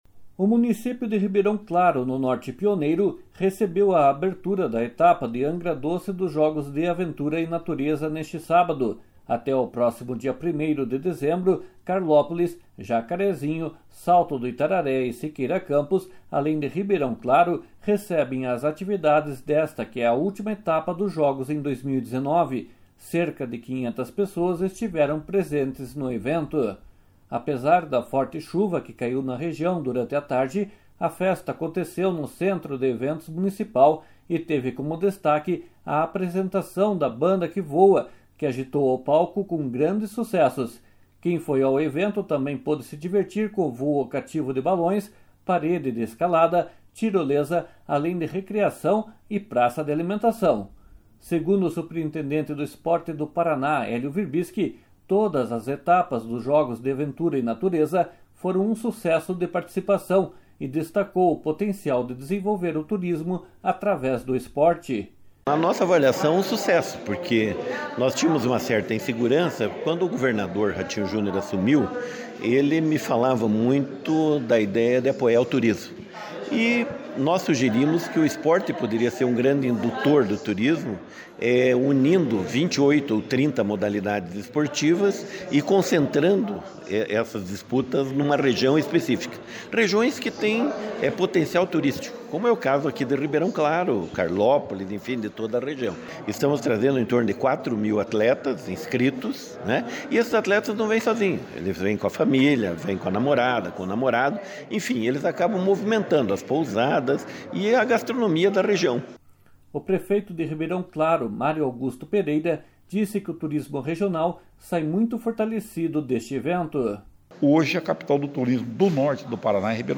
// SONORA HELIO WIRBISKI //
O prefeito de Ribeirão Claro, Mario Augusto Pereira, disse que o turismo regional sai muito fortalecido deste evento. // SONORA MARIO AUGUSTO PEREIRA //